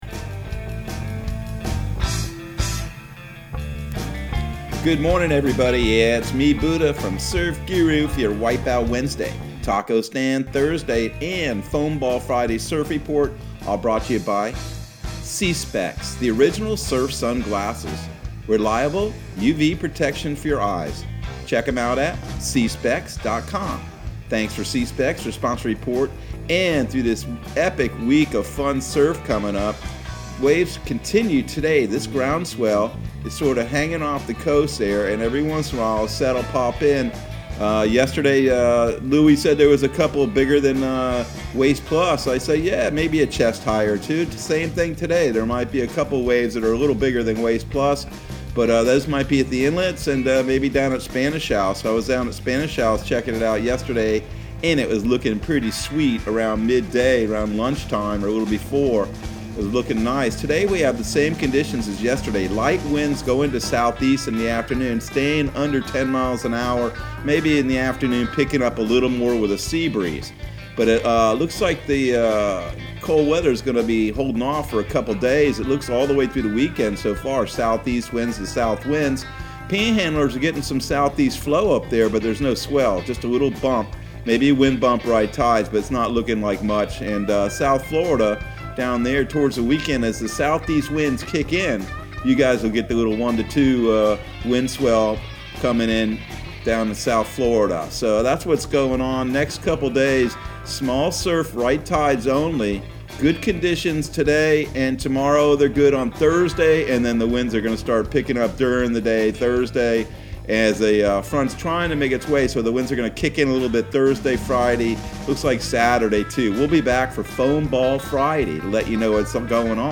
Surf Guru Surf Report and Forecast 02/10/2021 Audio surf report and surf forecast on February 10 for Central Florida and the Southeast.